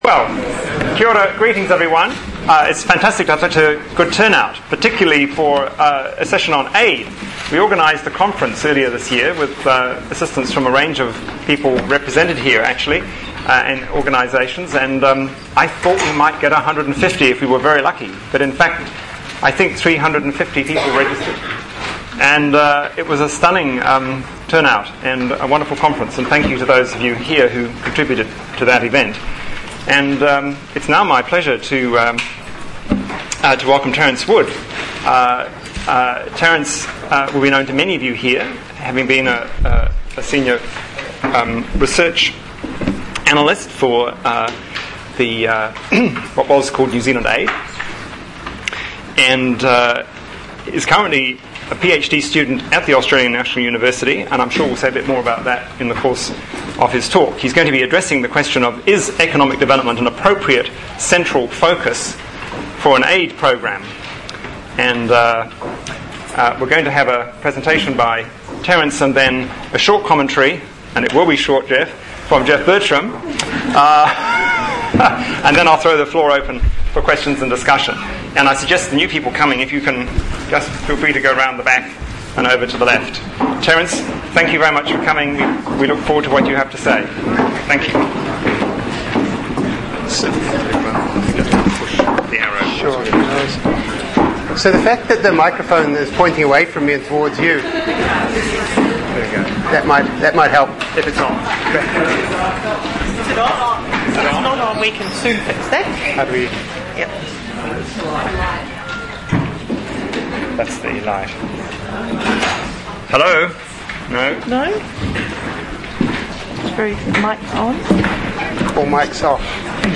In late March NZADDs hosted speaking events in Auckland, Wellington and Dunedin.